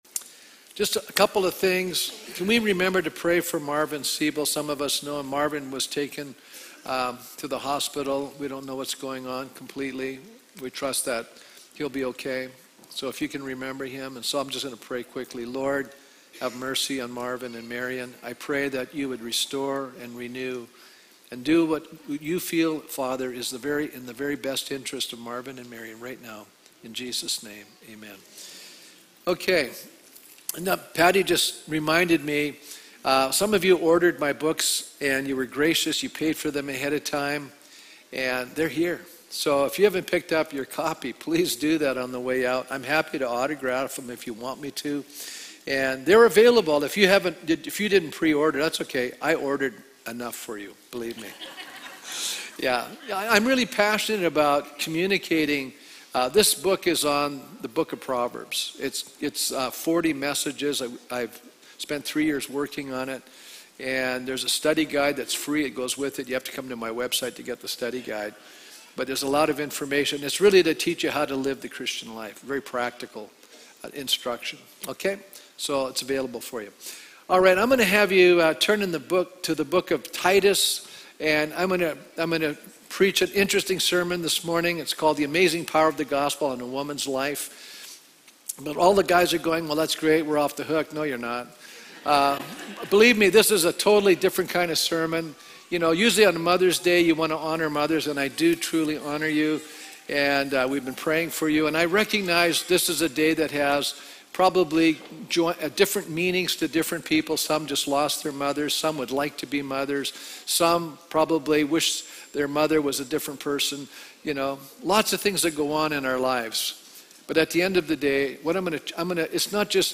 Living Stones Church, Red Deer, Alberta